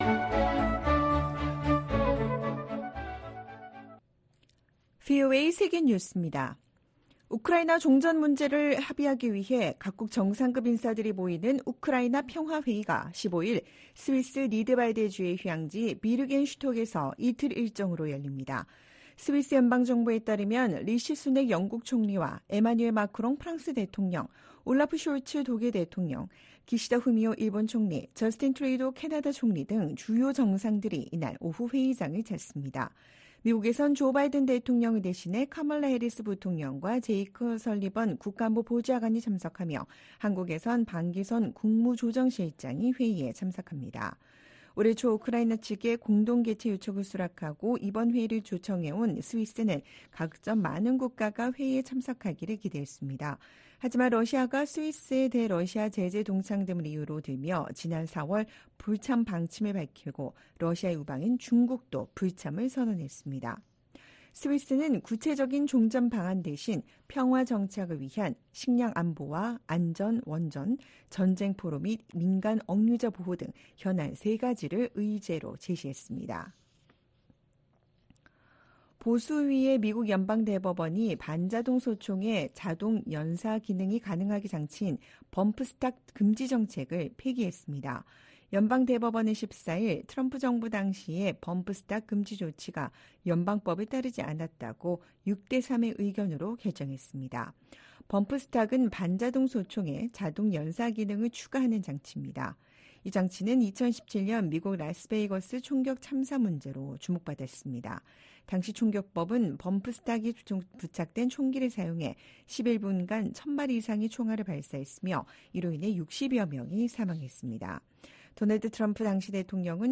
VOA 한국어 방송의 토요일 오후 프로그램 2부입니다. 한반도 시간 오후 9:00 부터 10:00 까지 방송됩니다.